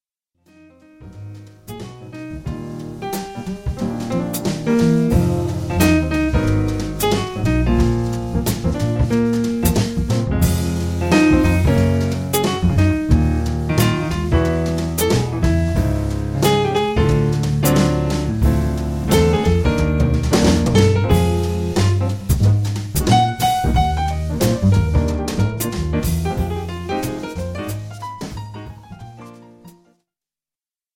tenor and soprano saxes
vibes and percussion
guitar
piano, keyboards and synthesizer
double bass
electric bass
drums